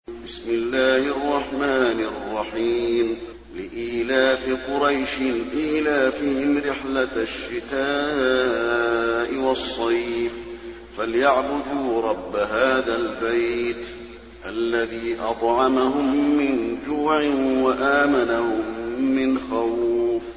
المكان: المسجد النبوي قريش